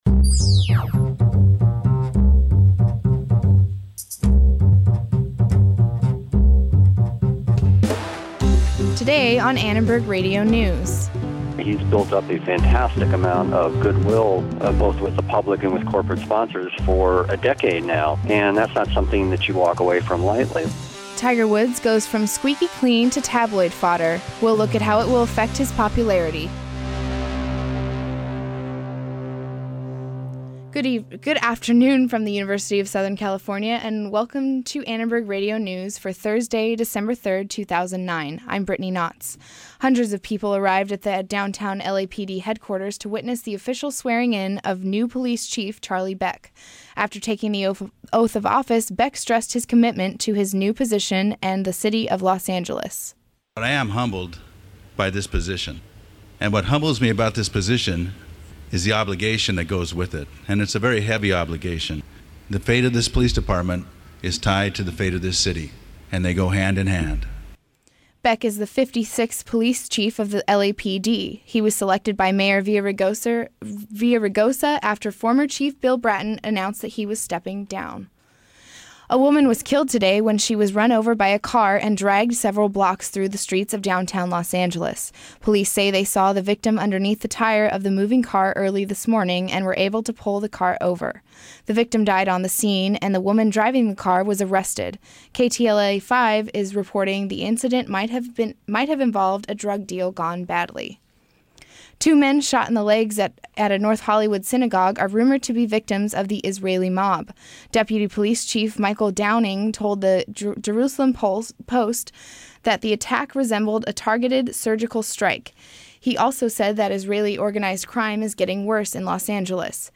LAPD Chief Charlie Beck was formally sworn in today. Hear what happened at the ceremony as well as the advice he got from community leaders.
We talk with community members about what they think about the golf superstar and hear an L.A. Times reporter's take.